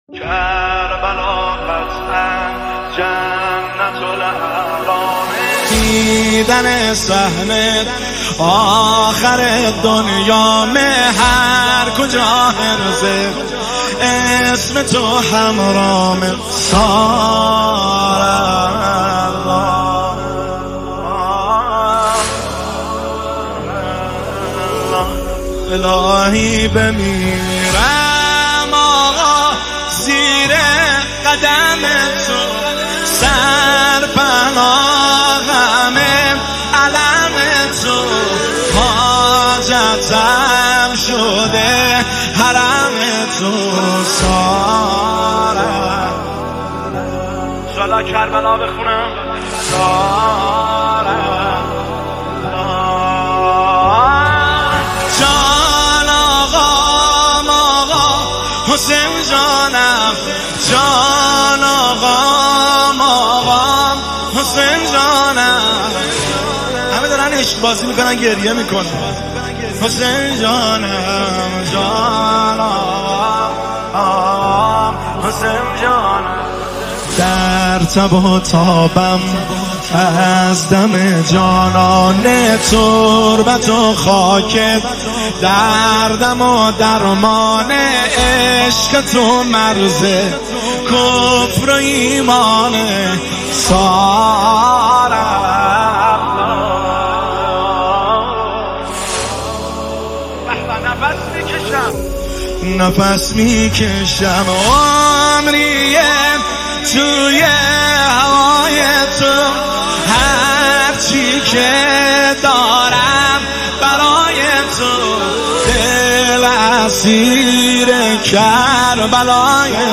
نماهنگ زیبای